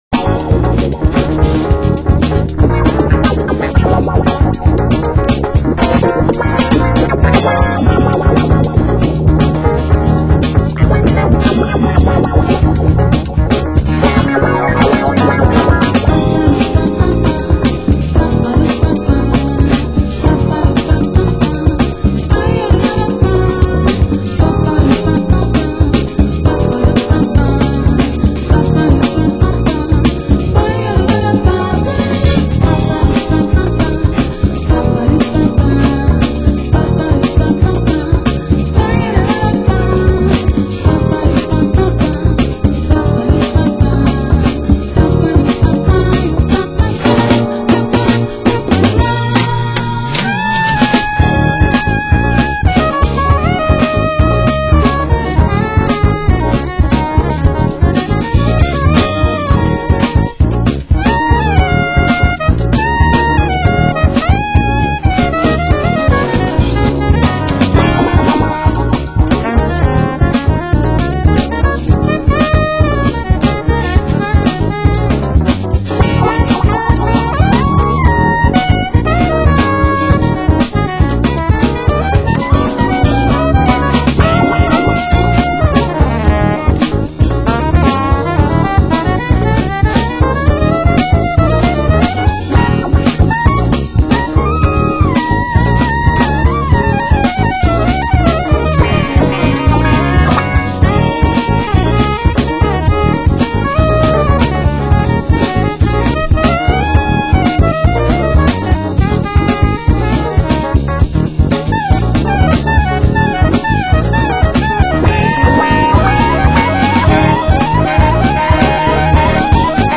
Genre: Soul & Funk